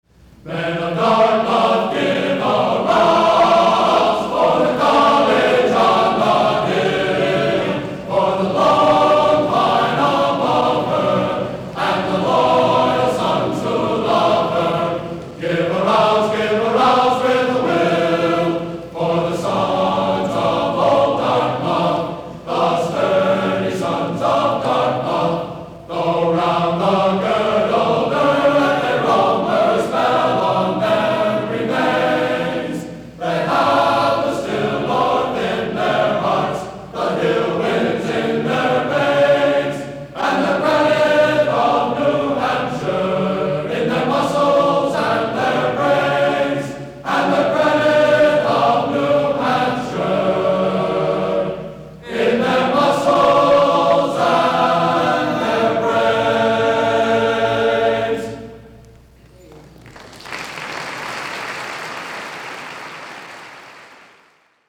Part of Men of Dartmouth (Glee Club)